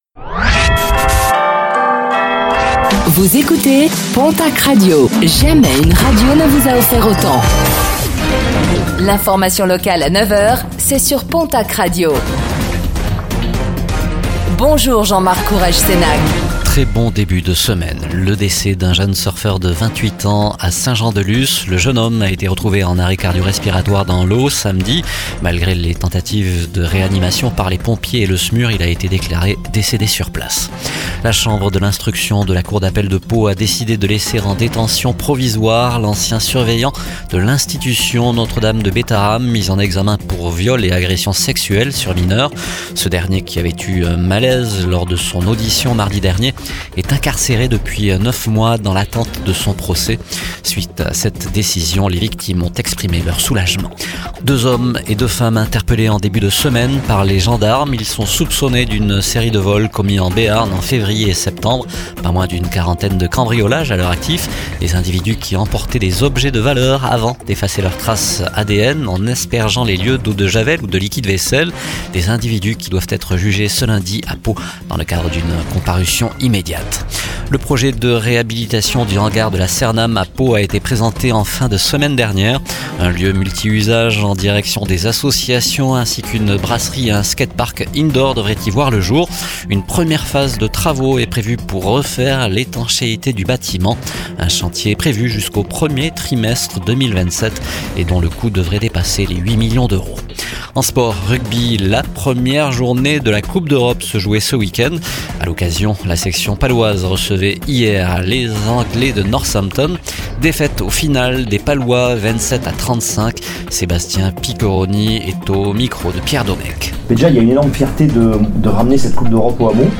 Réécoutez le flash d'information locale de ce lundi 08 décembre 2025